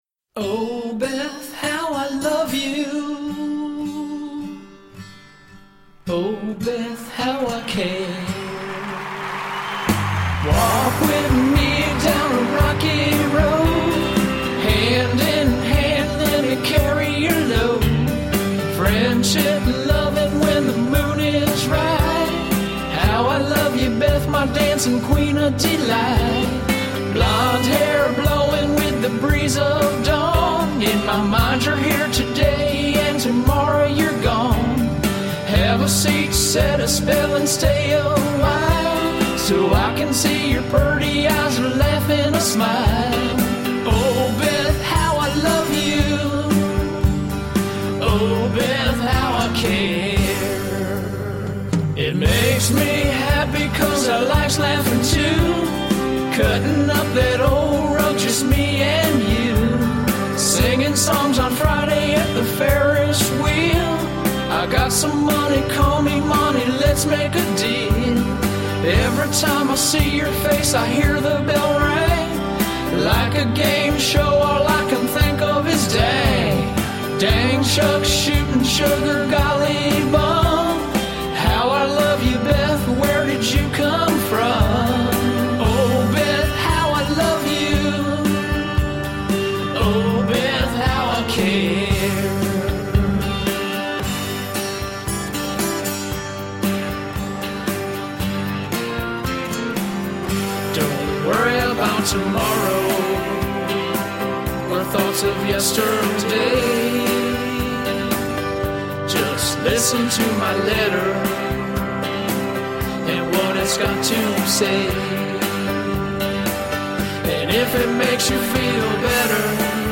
• 5 hours in the studio